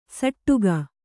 ♪ saṭṭuga